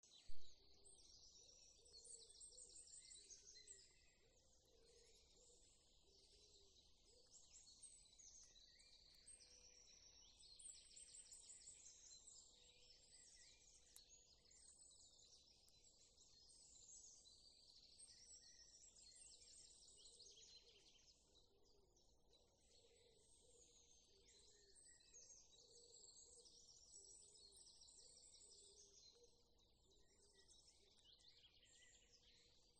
Birds -> Doves ->
Wood Pigeon, Columba palumbus
StatusSinging male in breeding season